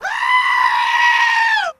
Play, download and share Schreien original sound button!!!!
schreien.mp3